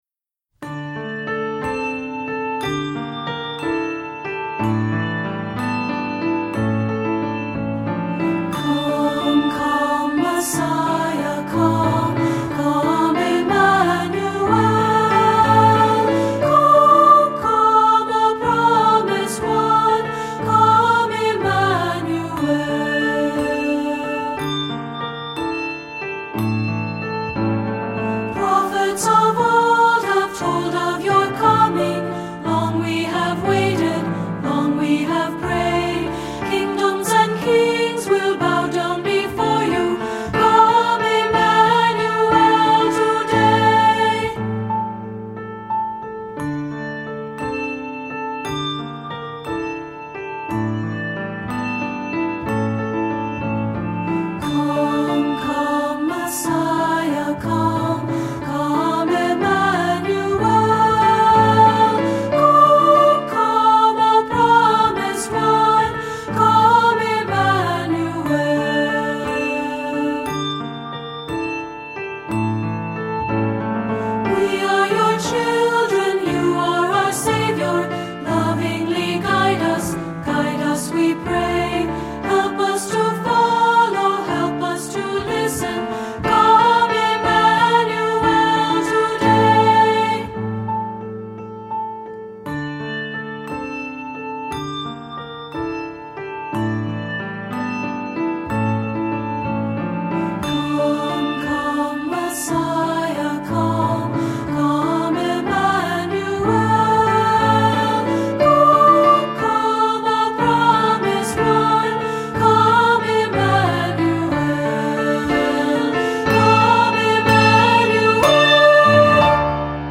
Listen to Full Demo Tracks: